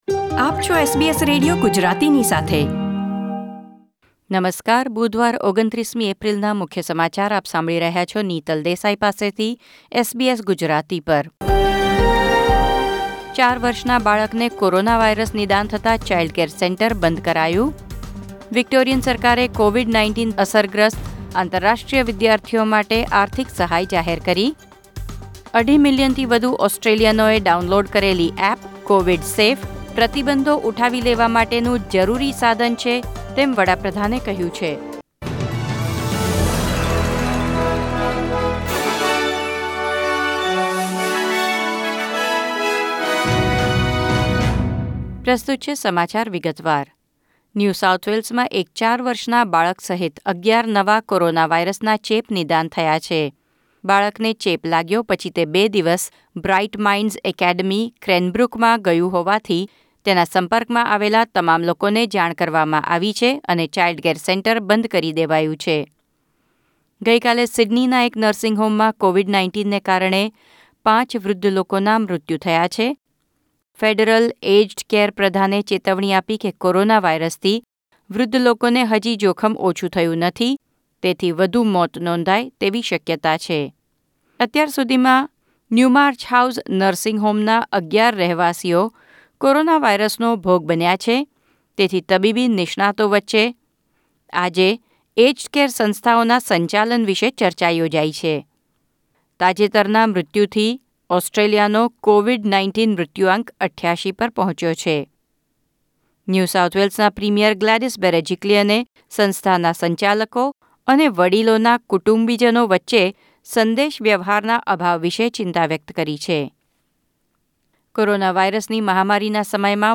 SBS Gujarati News Bulletin 29 April 2020